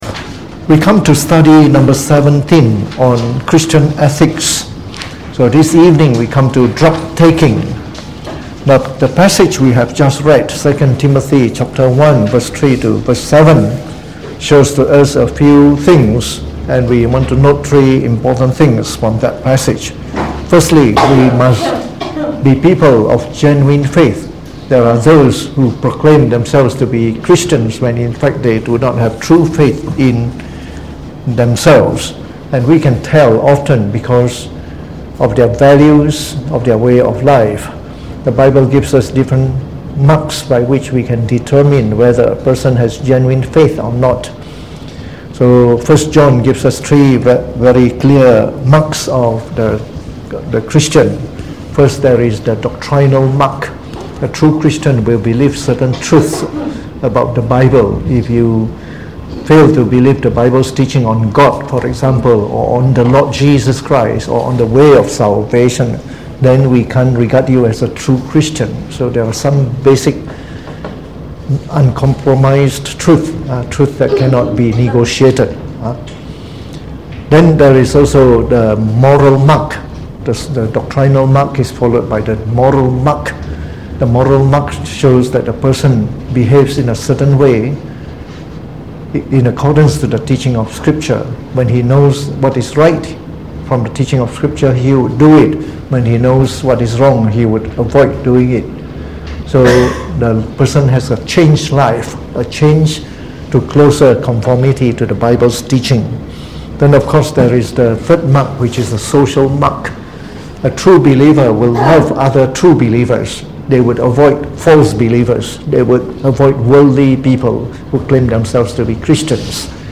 Delivered on the 3rd of July 2019 during the Bible Study, from our series on Christian Ethics.